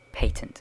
Ääntäminen
IPA : /ˈpeɪtənt/